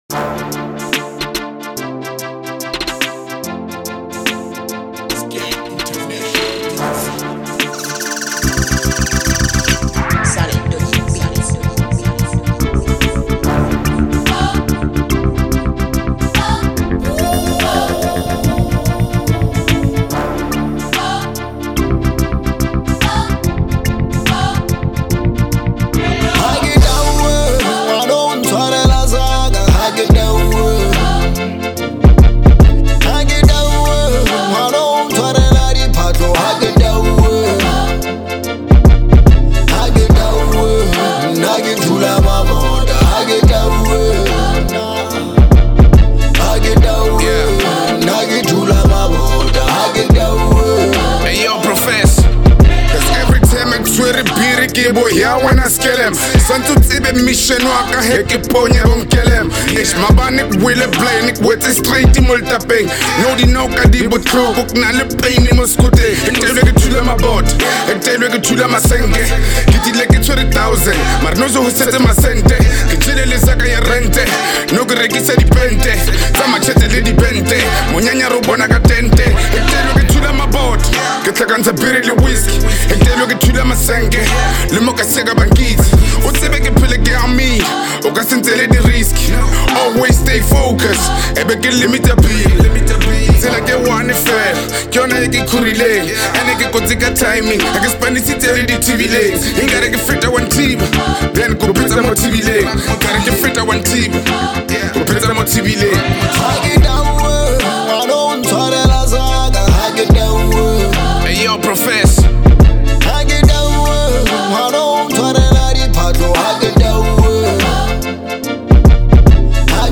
03:53 Genre : Trap Size